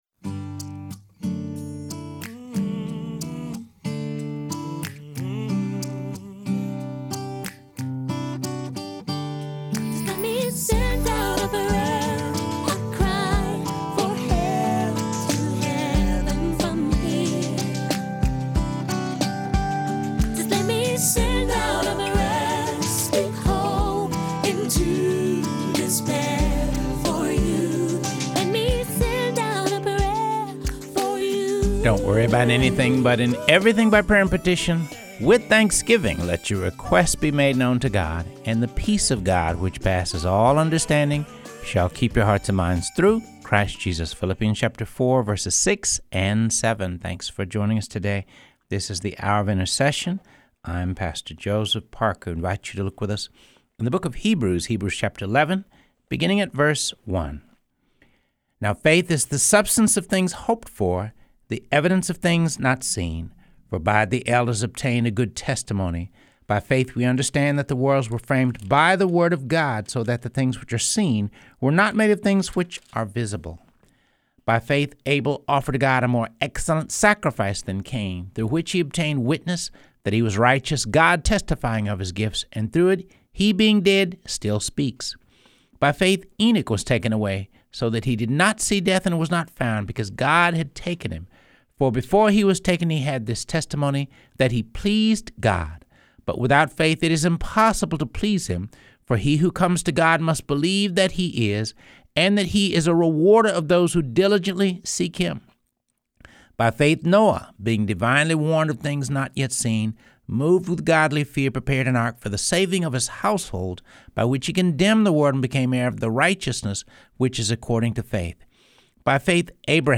Reading through the Word of God | Episode 70